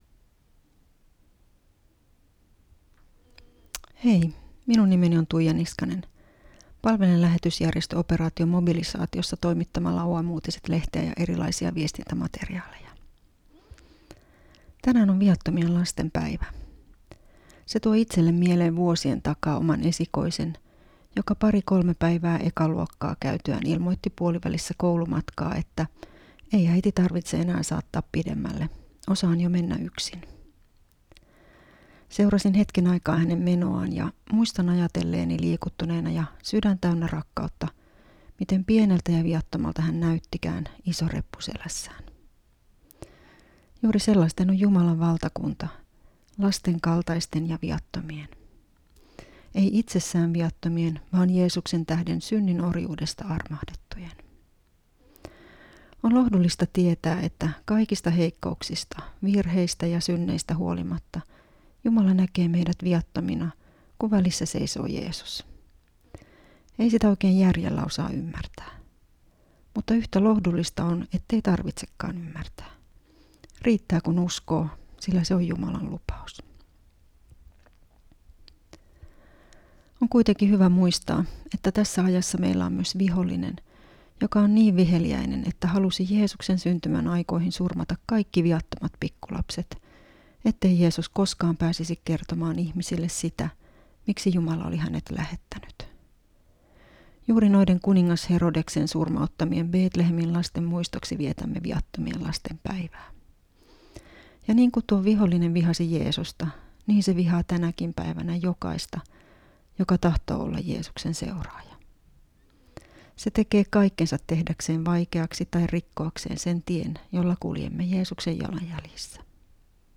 Teksti on radiohartaus